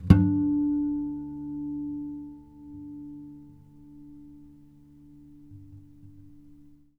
harmonic-06.wav